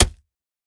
Minecraft Version Minecraft Version latest Latest Release | Latest Snapshot latest / assets / minecraft / sounds / block / packed_mud / break6.ogg Compare With Compare With Latest Release | Latest Snapshot